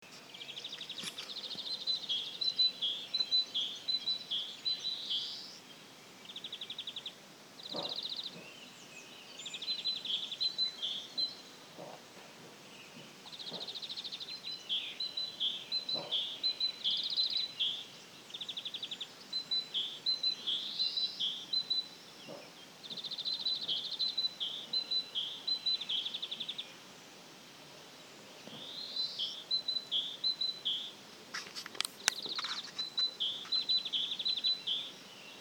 большая синица, Parus major
СтатусПоёт